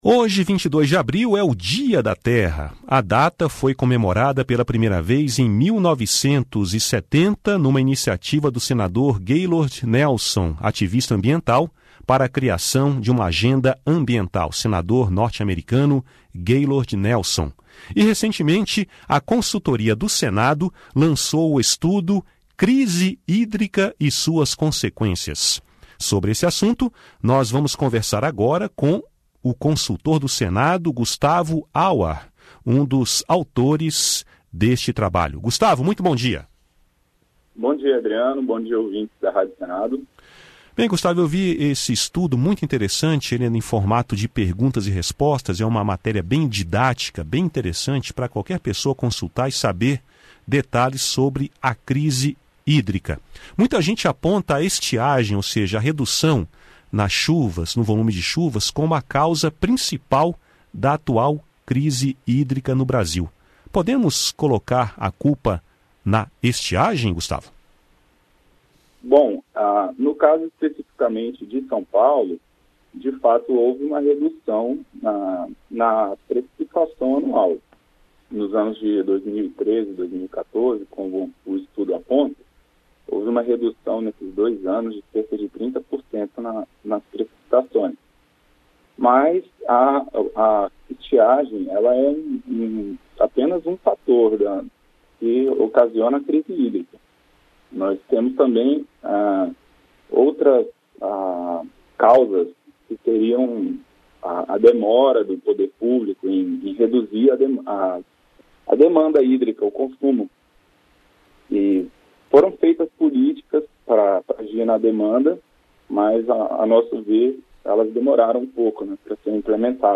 Em entrevista ao programa Conexão Senado, da Rádio Senado